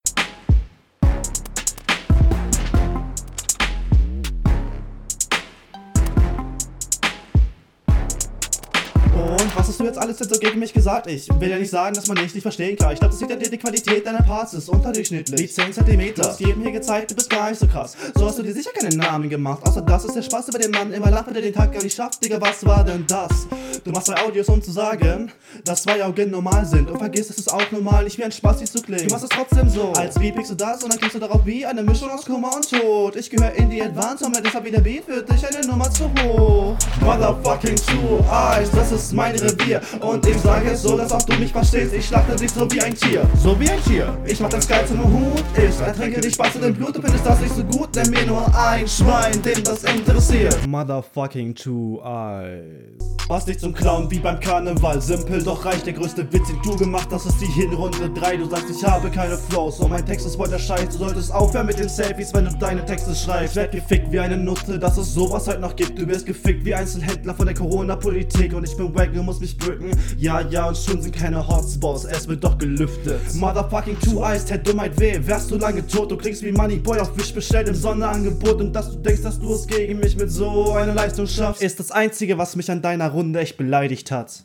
Dein Einstieg gefällt mir sehr gut ,der Doubletime , die Verständlichkeit und die Technik ist …
auf dem ersten beat gefällst du mir besser, flow ist hier echt ganz nice, konter …